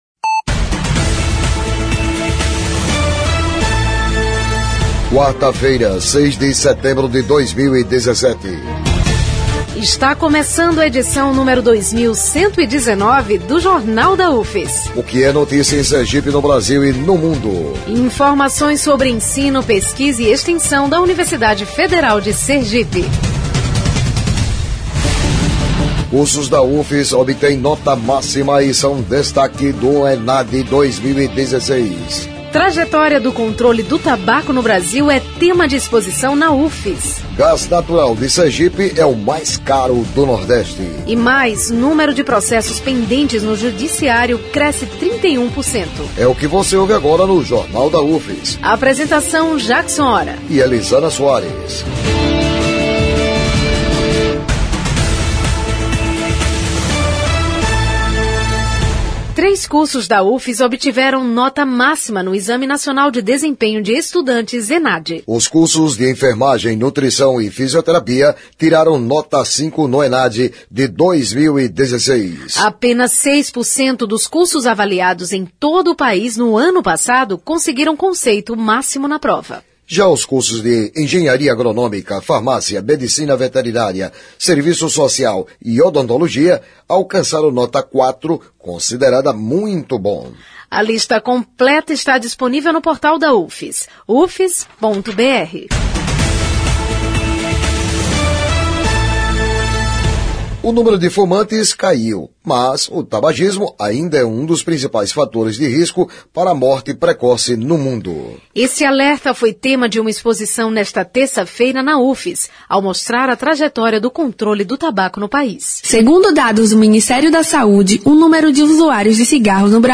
O noticiário vai ao ar às 11:00 na Rádio UFS FM 92,1 MHz, com reprises às 17:00 e 22:00.